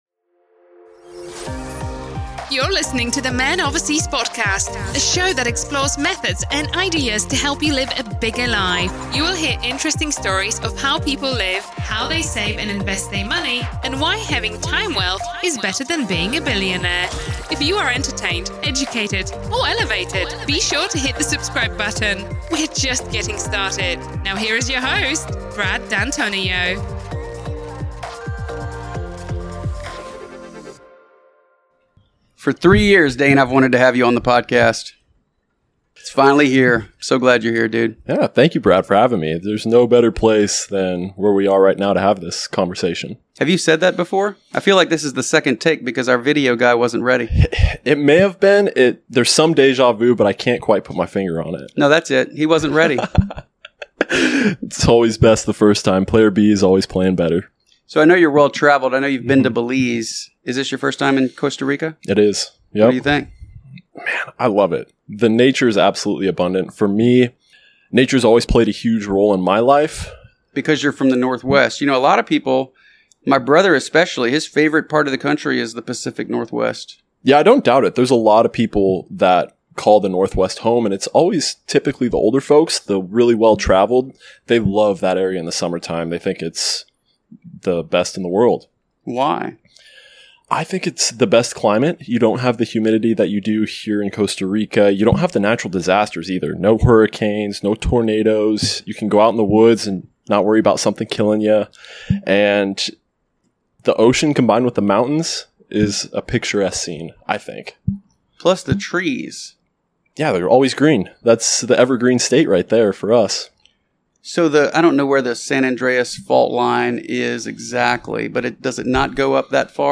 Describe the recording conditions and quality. In this episode recorded at the Man Overseas Retreat in Costa Rica